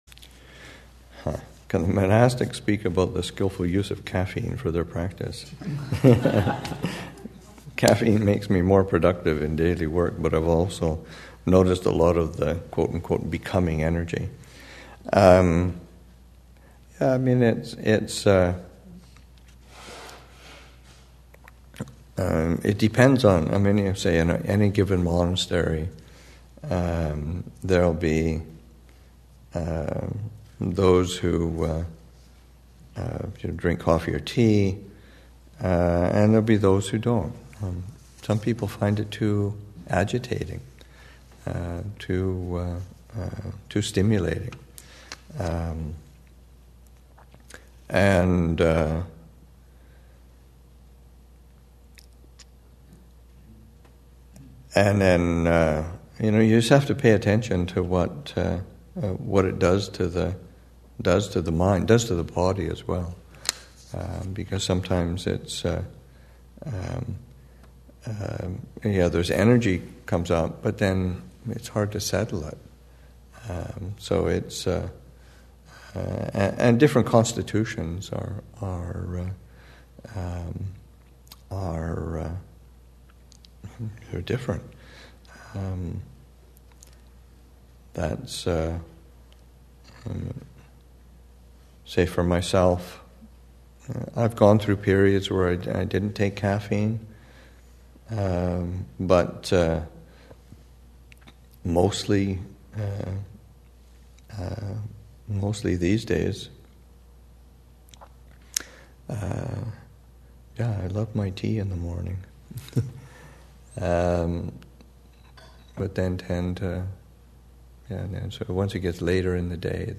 2015 Thanksgiving Monastic Retreat, Session 6 – Nov. 26, 2015